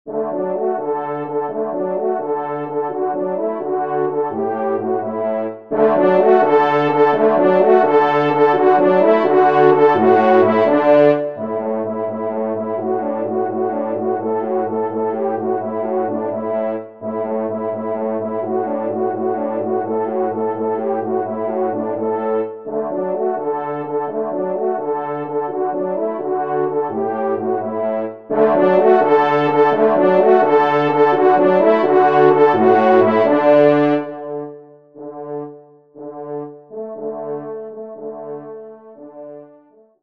3e Trompe